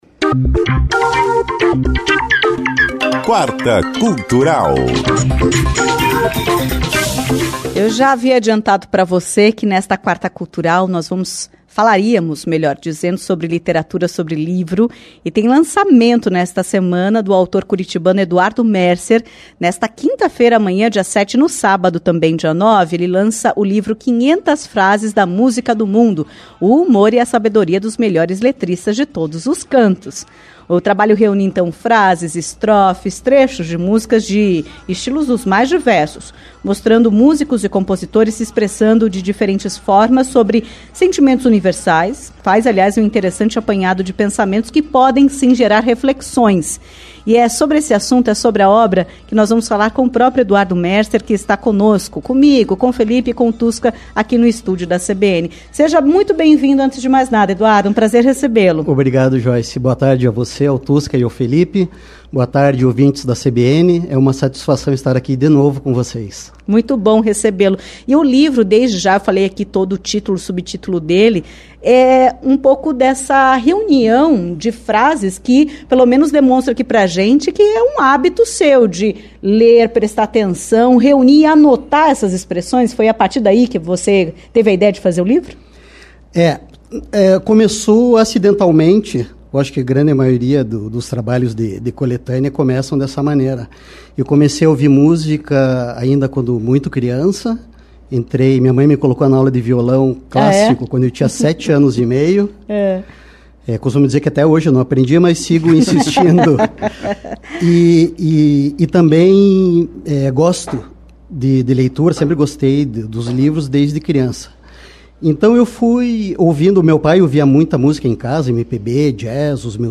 Entrevista-6-11.mp3